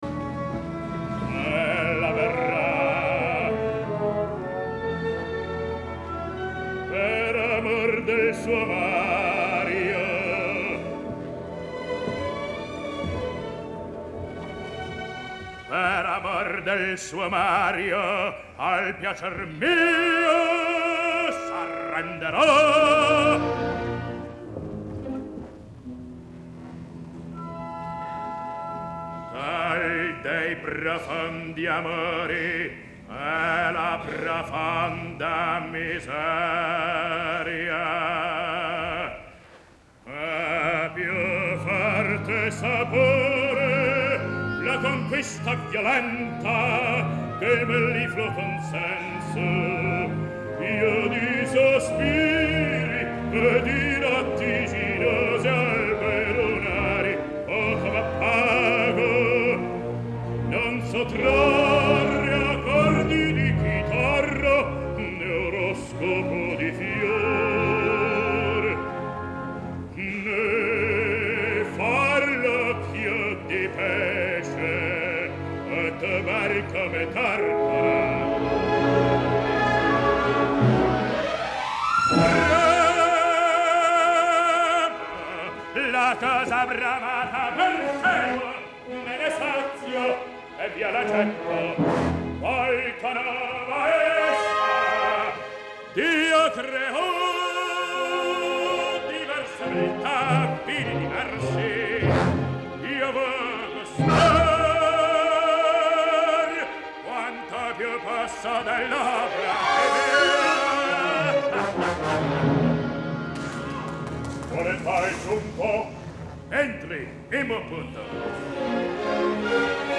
Genre : Classique